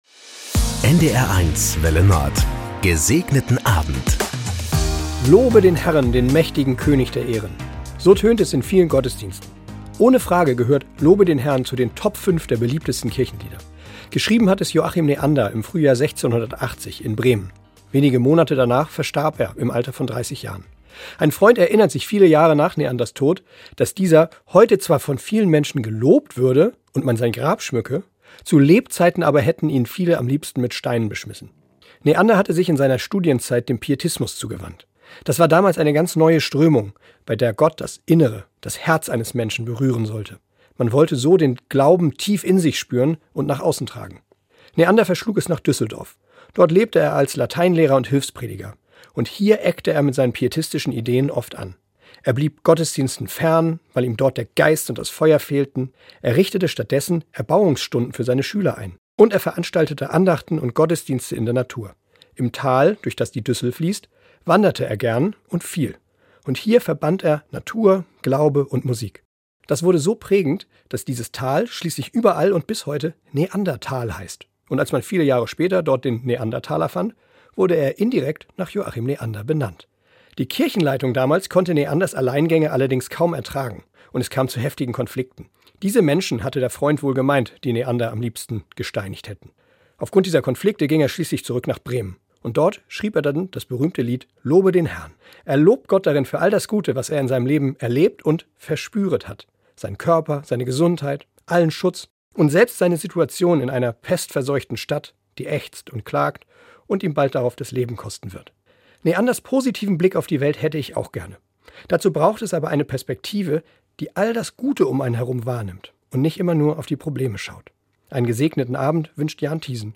Das gute Wort zum Feierabend auf NDR 1 Welle Nord mit den Wünschen für einen "Gesegneten Abend".
Täglich um 19.04 Uhr begleiten wir Sie mit einer Andacht in den Abend - ermutigend, persönlich, aktuell, politisch, tröstend.